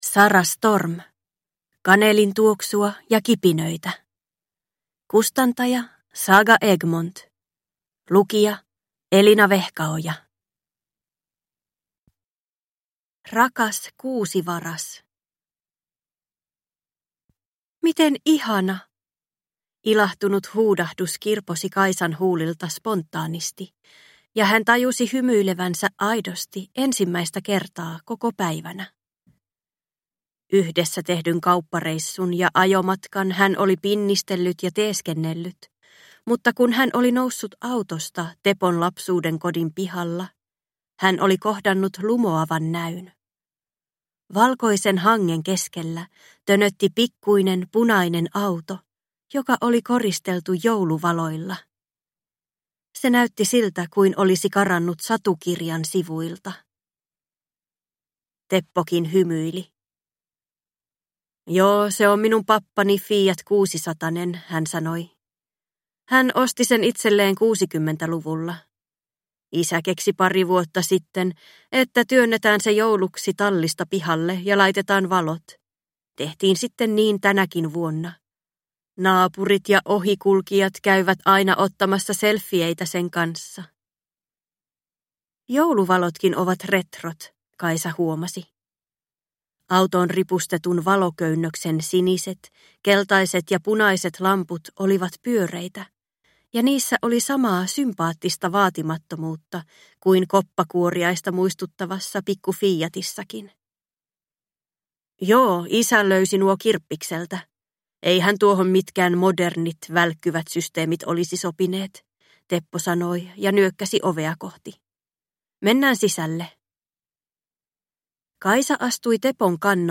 Kanelin tuoksua ja kipinöitä – Ljudbok